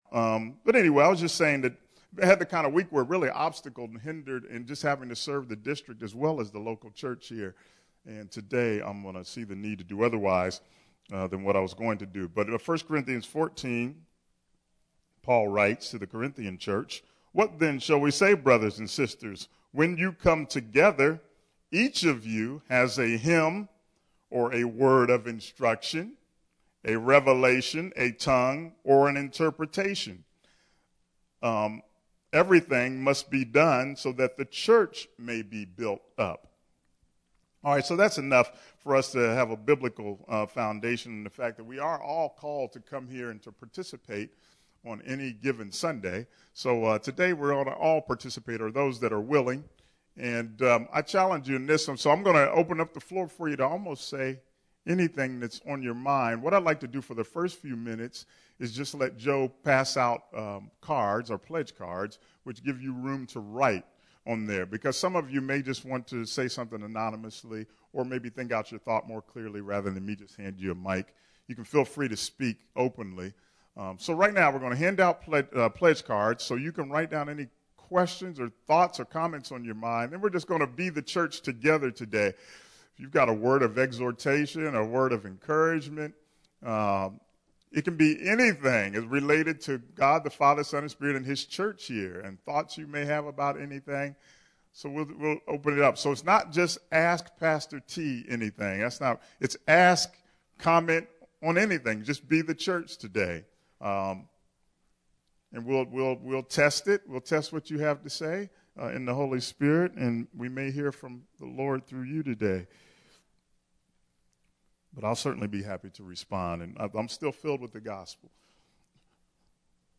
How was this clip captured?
– NIV We at New Life Fellowship of Baltimore, Maryland decided to take part in the life this verse speaks of as we listened, heard and responded to each other as questions were raised, or comments and testimonies were made! Check it out and be very forgiving of our “off the cuffness”!